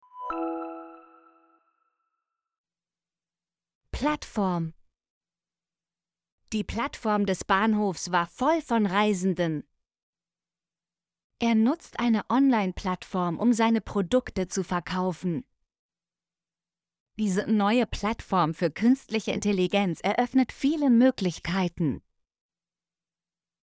plattform - Example Sentences & Pronunciation, German Frequency List